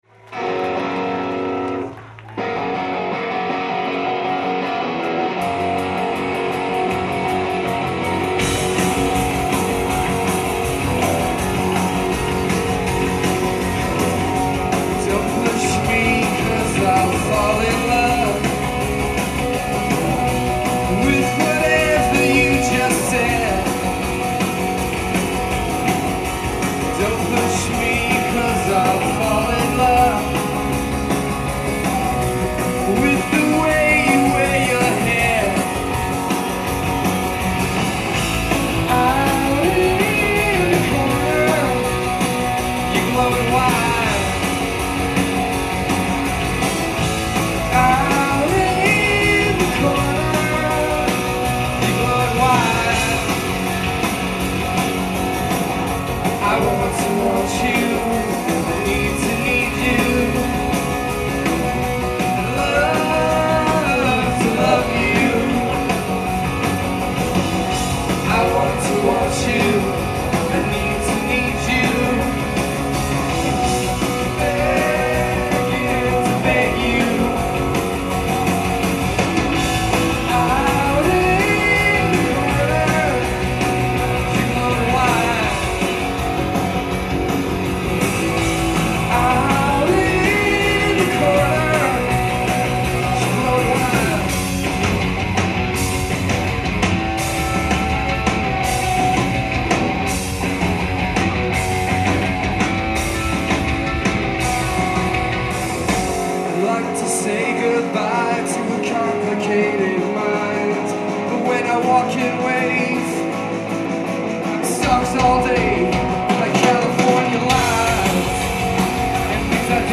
live at Higher Ground
South Burlington, Vermont